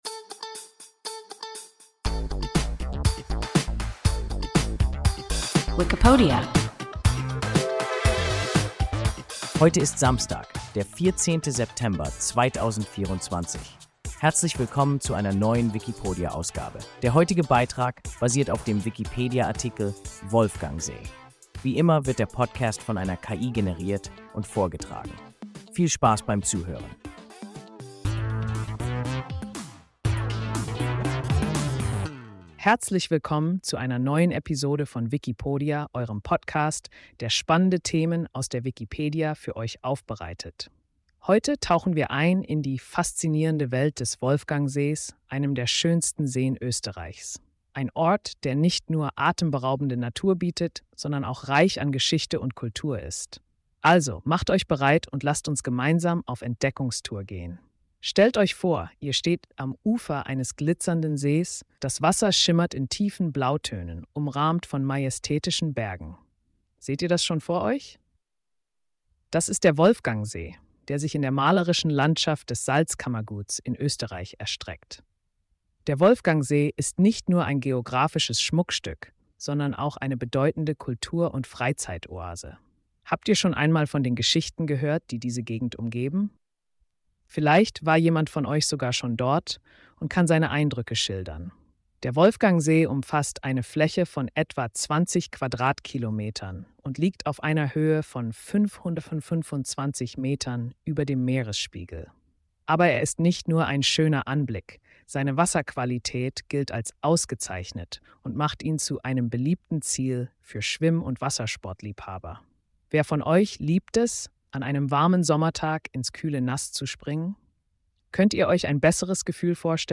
Wolfgangsee – WIKIPODIA – ein KI Podcast